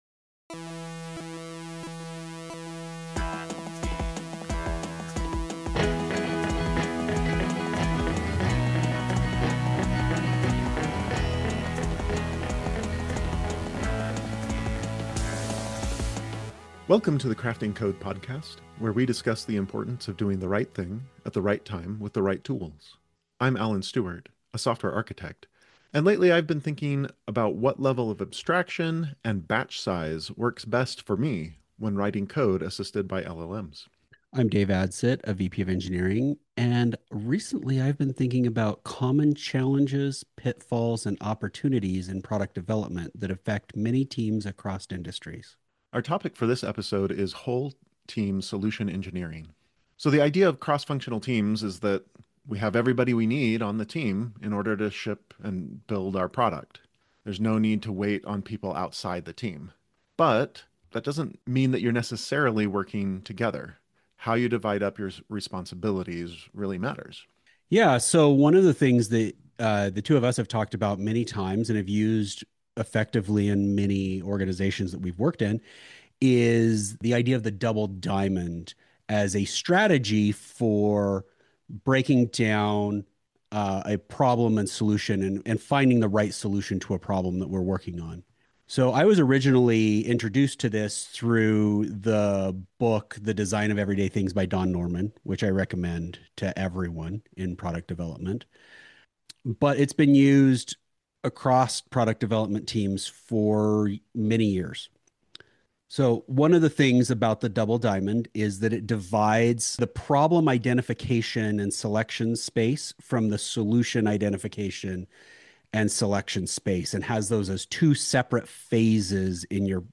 In this episode, your hosts discuss the Product Discovery Double Diamond concept, using techniques like '5 Whys' to dig into problems, and letting teams own their problem space. These ideas have helped us move from mere outputs to outcomes and real impact.